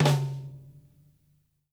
SNARE+HIGH-R.wav